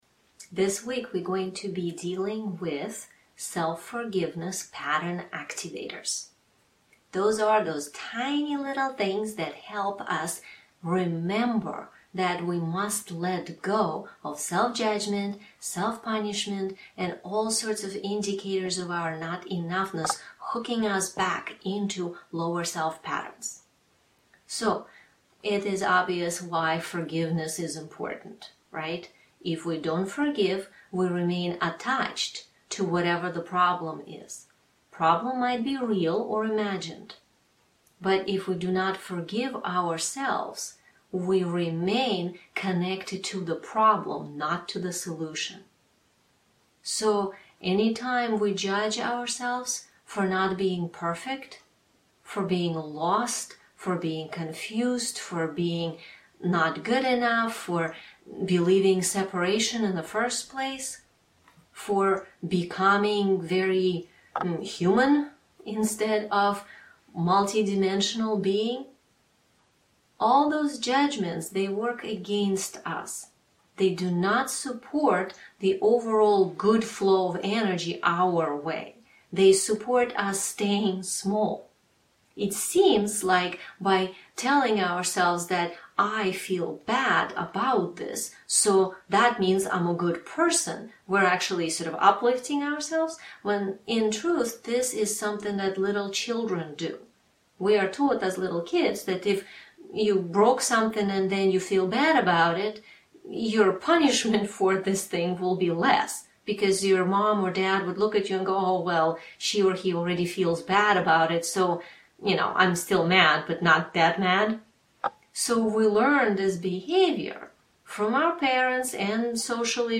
2- DOWNLOAD the AUDIO into your phone/computer so that you can listen to this meditation again and PRACTICE;